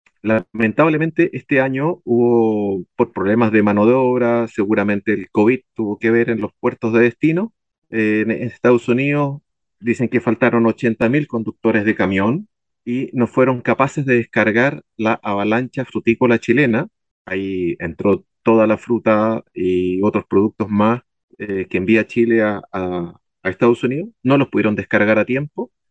En conversación con Radio Sago